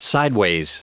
Prononciation du mot sideways en anglais (fichier audio)
Prononciation du mot : sideways